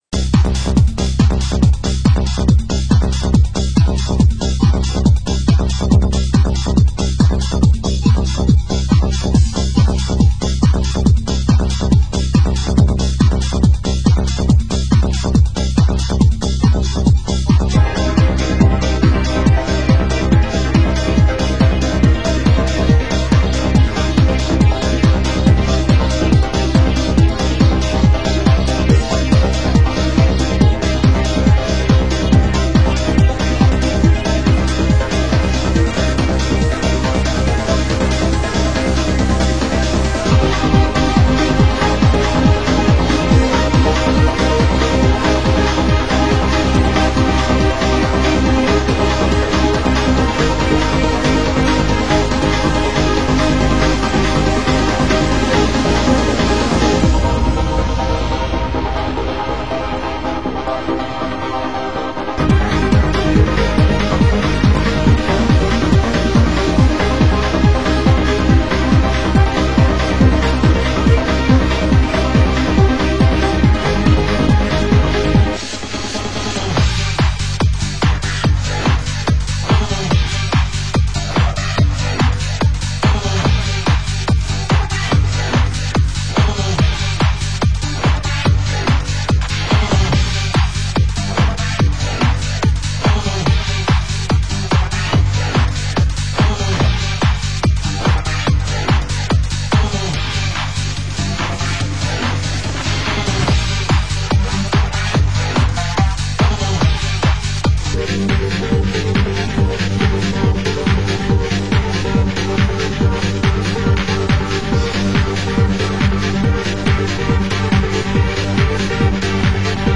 Format: Vinyl 12 Inch
Genre: Trance